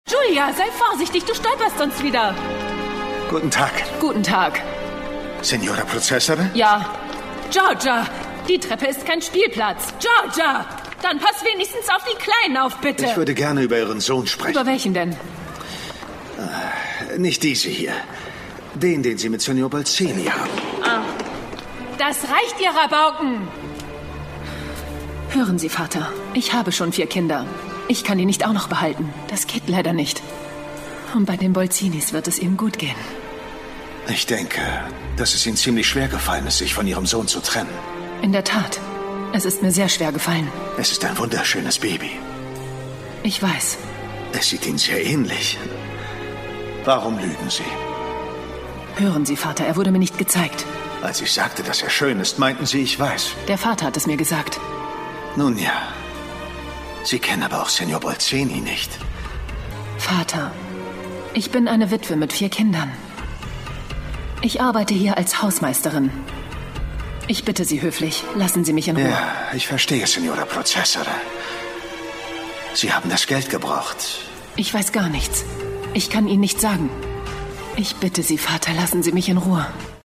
Werbung - Gymondo